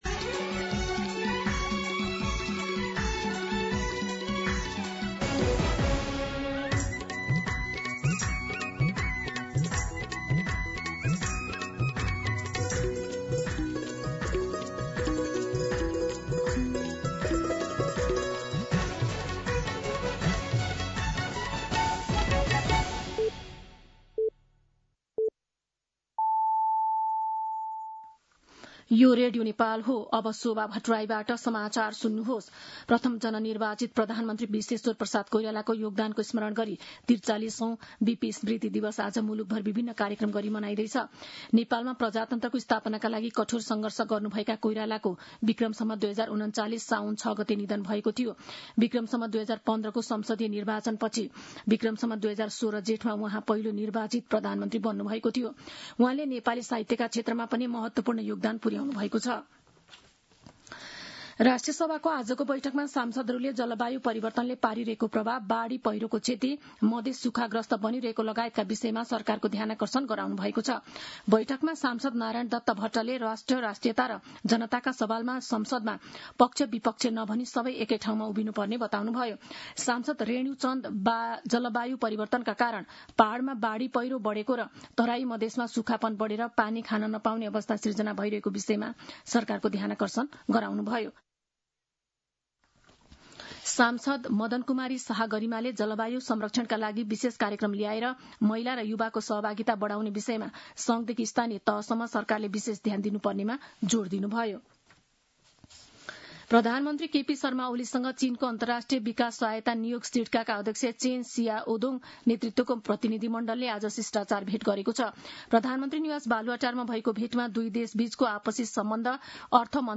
दिउँसो ४ बजेको नेपाली समाचार : ६ साउन , २०८२
4pm-News-06.mp3